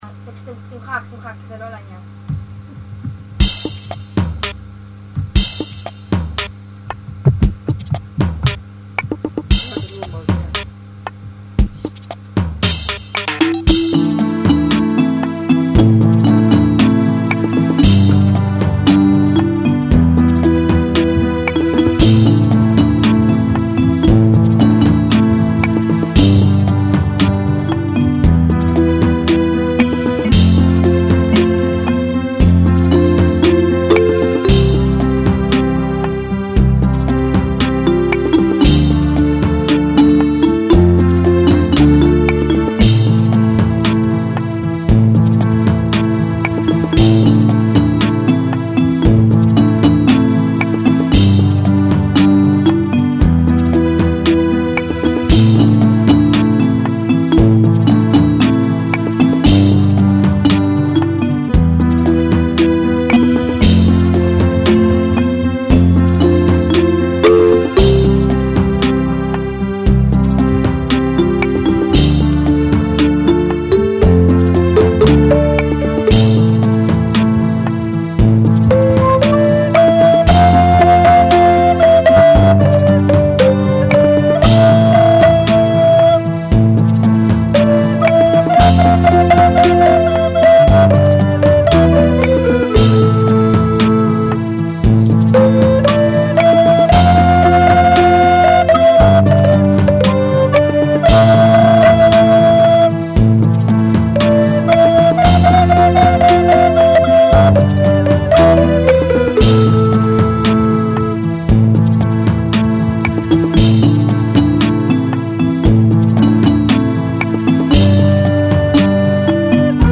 שומעים שזה מוזיקה אלקטרונית... אני צודק?
חוץ מהחלילית.